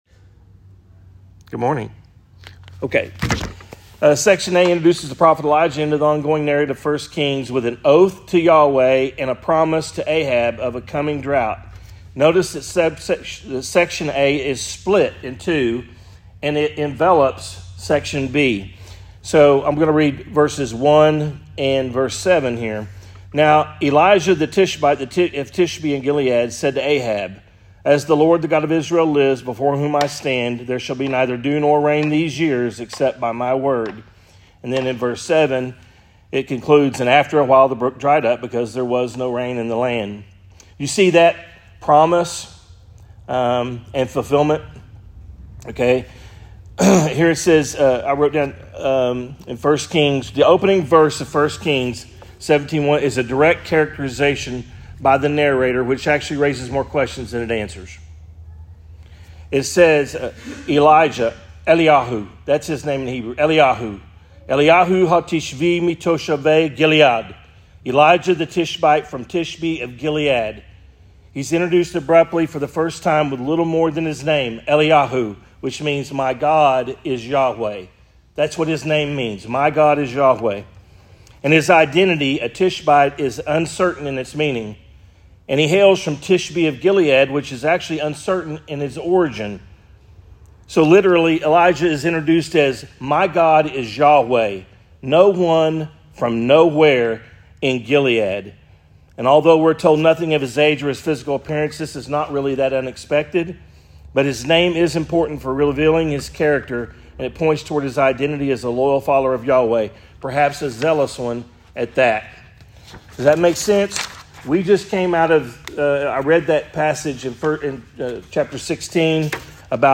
Adult Sunday School - 1 Kings 17